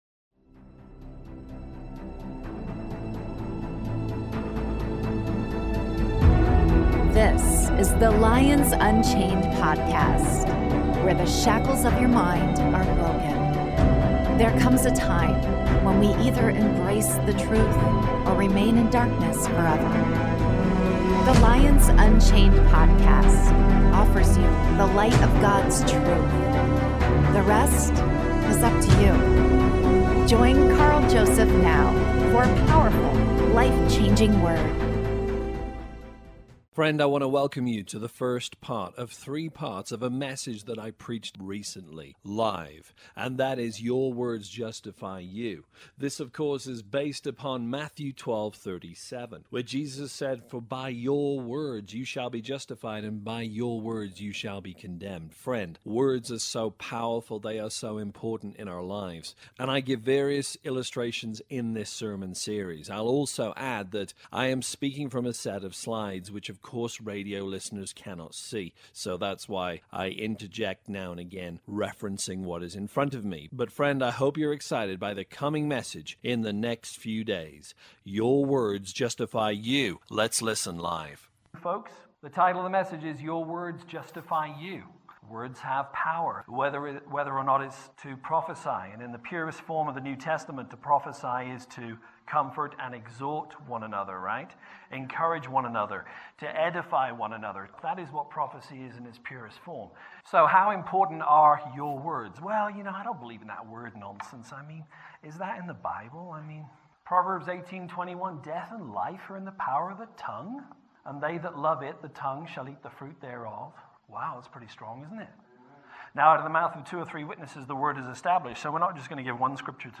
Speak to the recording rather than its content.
Your Words Justify You: Part 1 (LIVE)